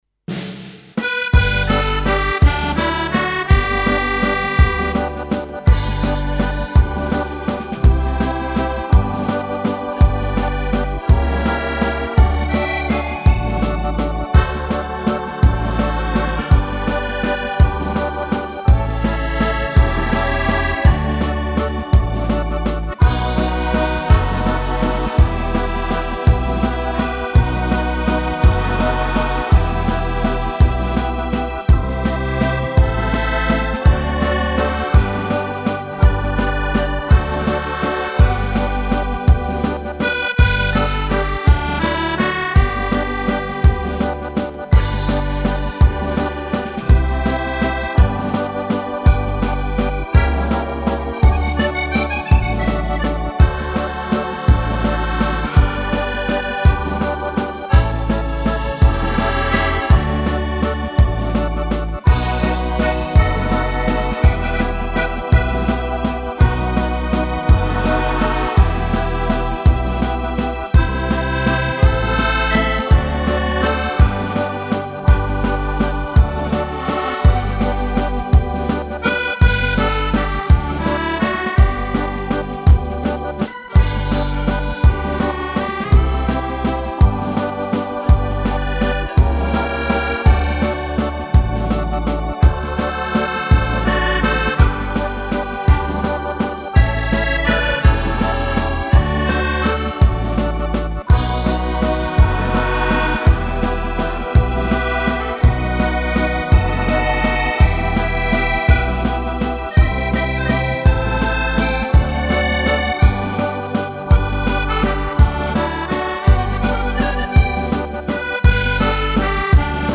Instrumentale uitvoering op keyboard